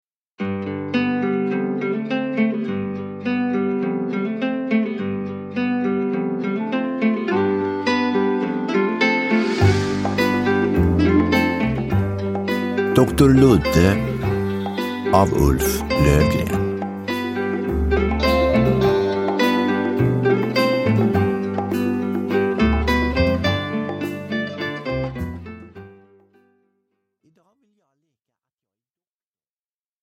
Doktor Ludde – Ljudbok – Laddas ner